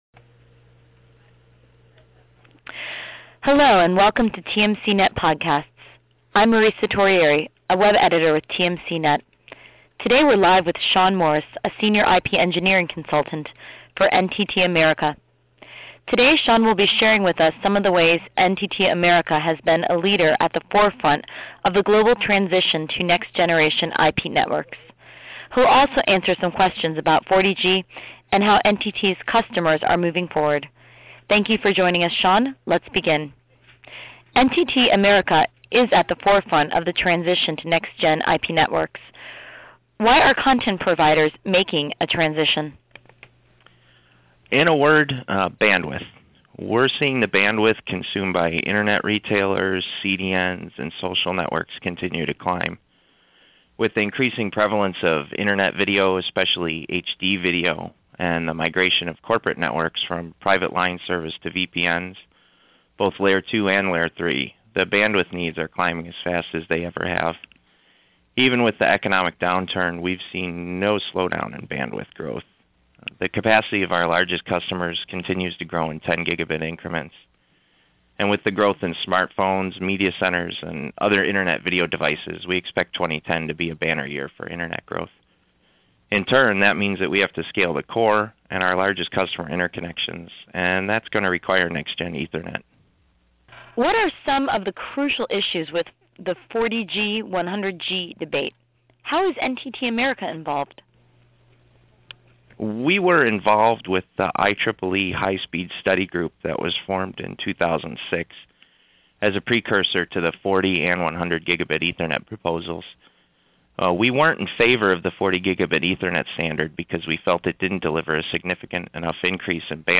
NTT_America_40g-100g_Interview.mp3